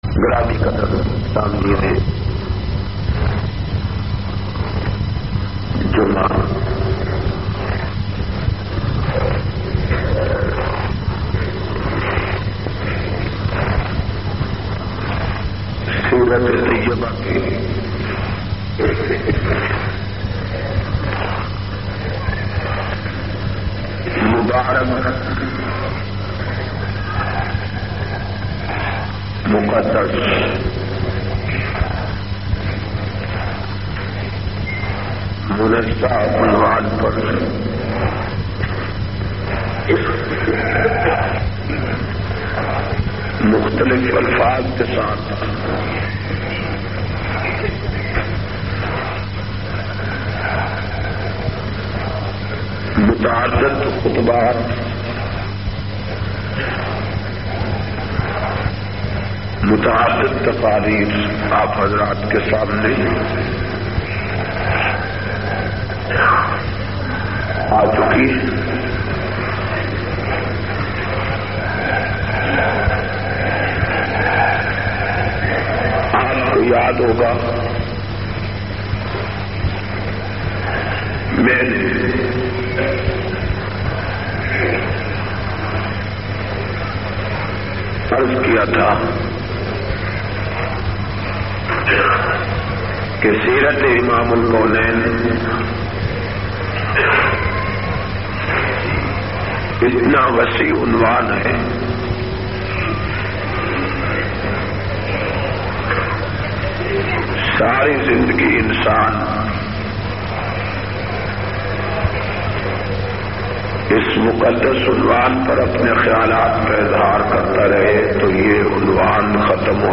358- Seerat Un Nabi-Jumma,Karachi.mp3